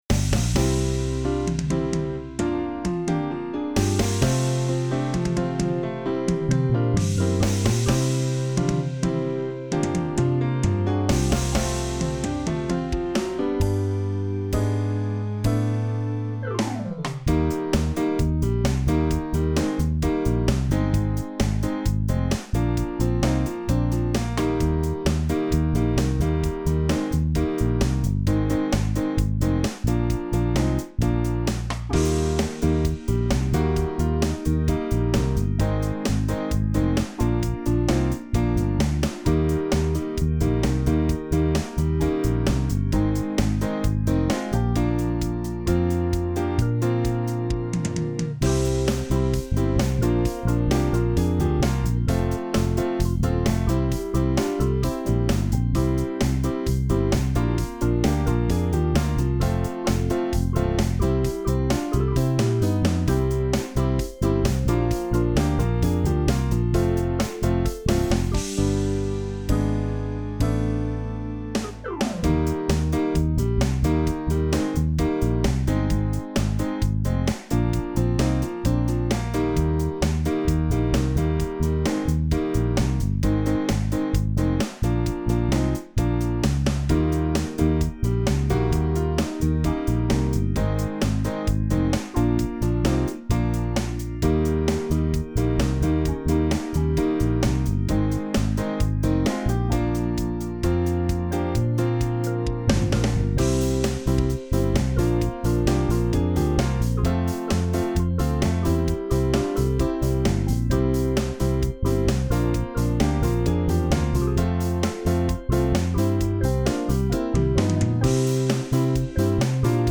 Daar geiht uns nix over (Playback)